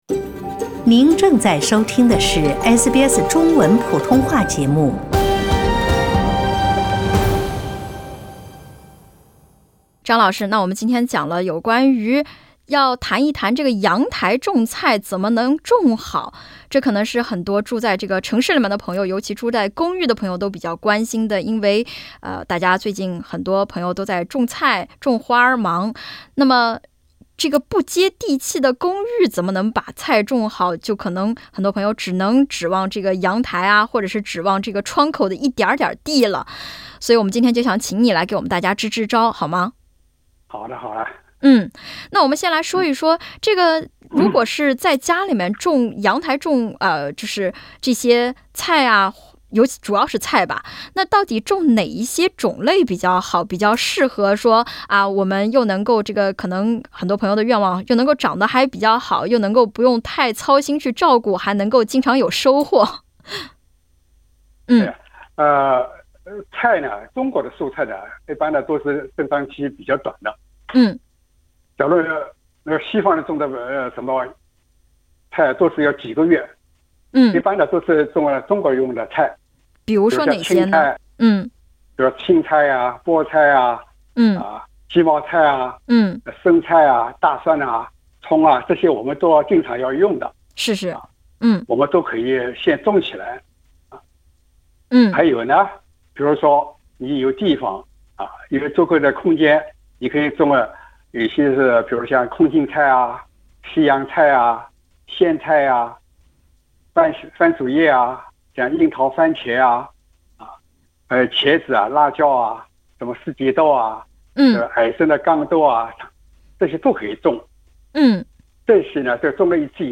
READ MORE 你问我答学施肥，他日丰收不是梦【我的花园，我的菜地】 他还回答了众多听友的提问，包括金桔新叶卷曲（长潜叶蛾）怎么办？桂花树怎么让它横向生长？泡沫箱种菜究竟有没有毒等，欢迎点击封面图片收听详细回答。 “我的花园，我的菜地”园艺热线，每月最后一个星期六播出。